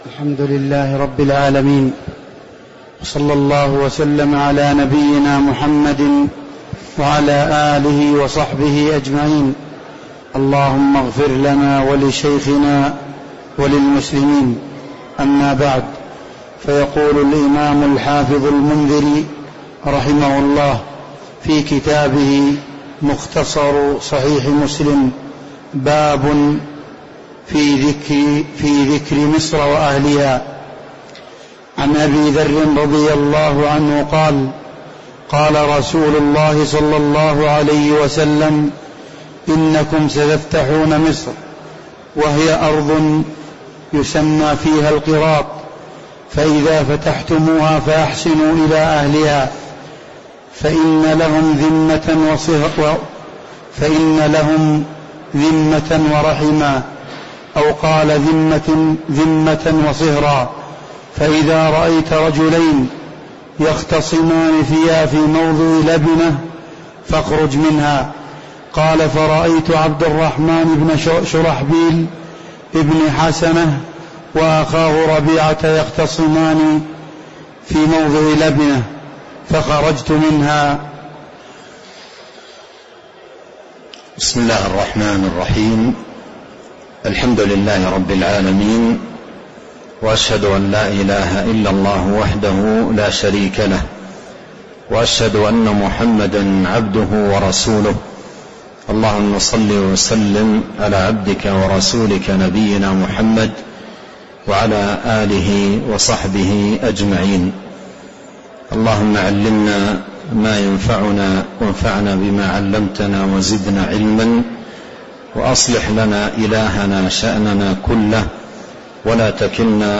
تاريخ النشر ٢٠ رمضان ١٤٤٣ هـ المكان: المسجد النبوي الشيخ: فضيلة الشيخ عبد الرزاق بن عبد المحسن البدر فضيلة الشيخ عبد الرزاق بن عبد المحسن البدر باب في ذكر مصر وأهلها (029)